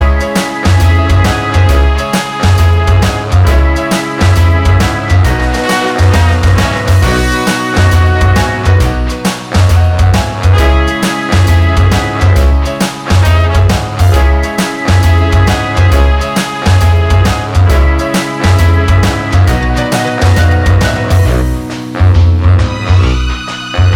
No Lead Guitar Pop (2000s) 3:07 Buy £1.50